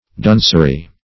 Duncery \Dun"cer*y\, n. Dullness; stupidity.